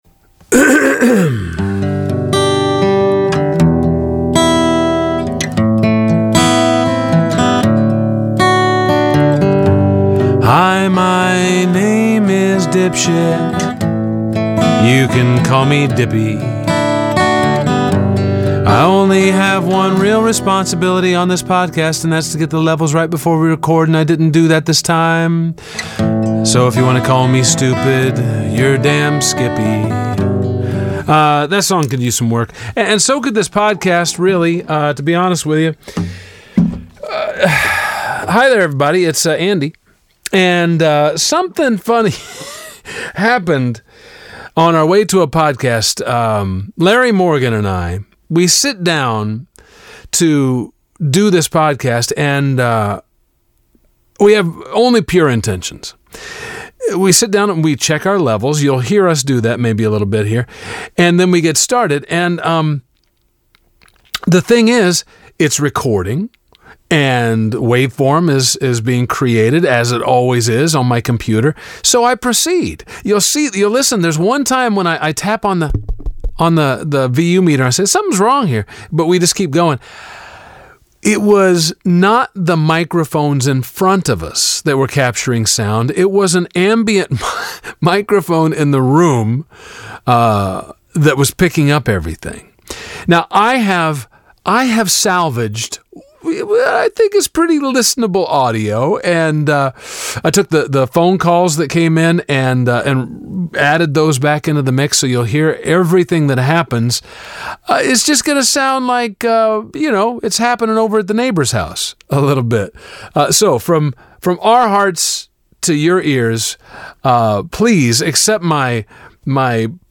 The sound quality is about that of a butt-dial on a cell phone, and just as interesting.